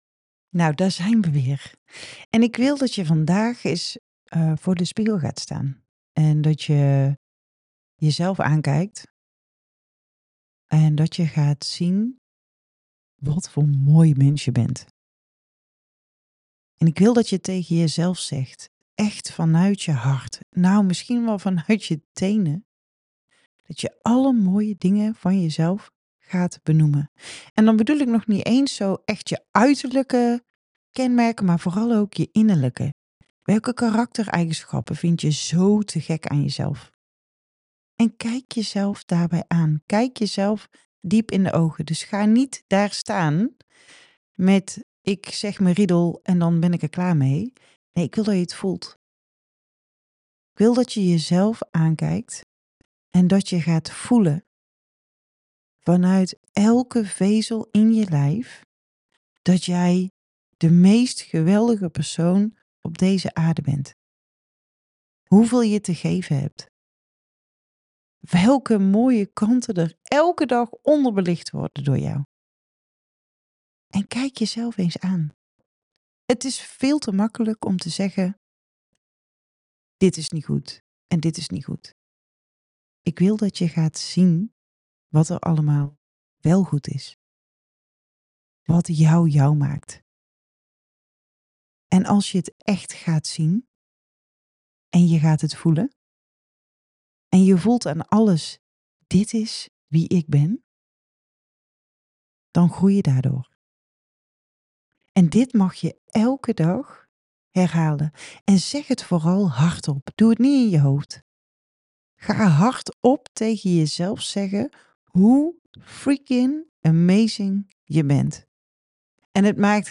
Vandaag daag ik je uit om voor de spiegel te gaan staan en te ontdekken wat jou zo bijzonder maakt. In het voicebericht van vandaag vertel ik hoe je jouw mooiste kanten in de spotlights kunt zetten én echt kunt voelen hoe geweldig je bent.